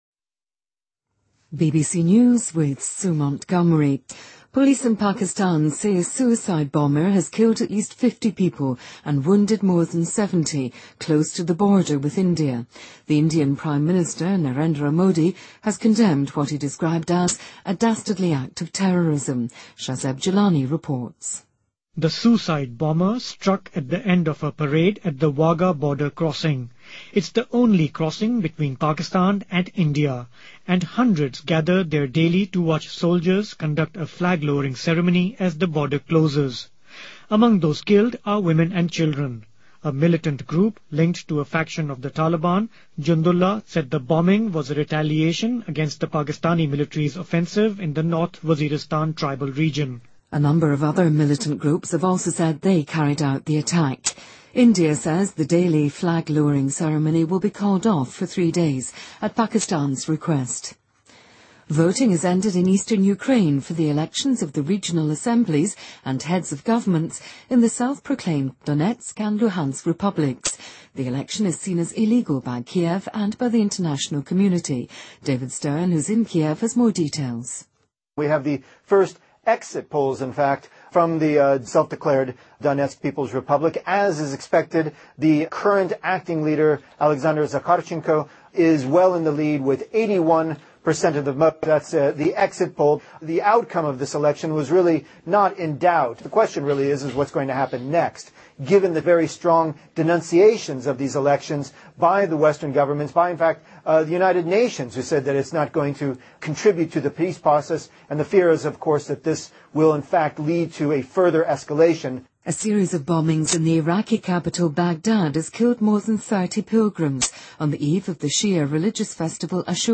BBC news,布基纳法索军队鸣枪示意在国家电视台楼前游行的群众解散